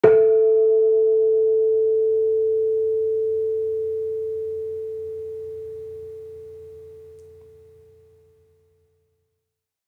Gamelan Sound Bank
Kenong-resonant-C4-f.wav